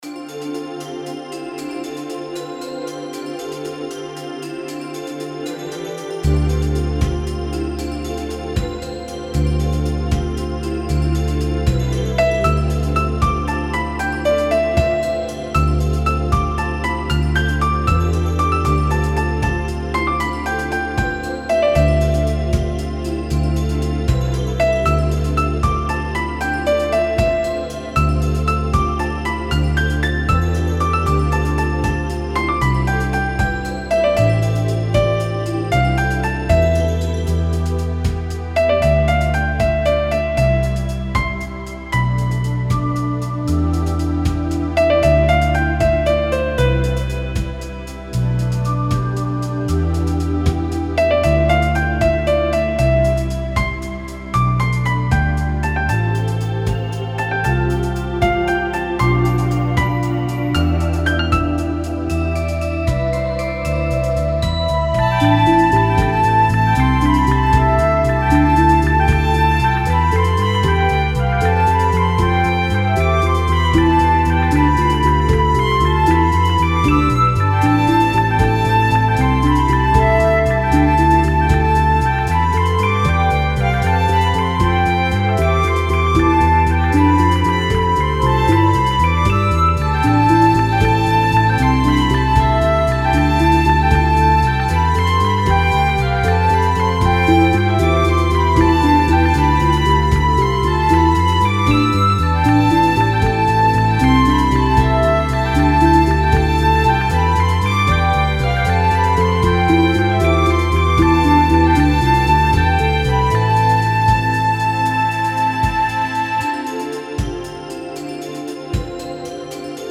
フリーBGM フィールド・ダンジョン ダンジョン
フェードアウト版のmp3を、こちらのページにて無料で配布しています。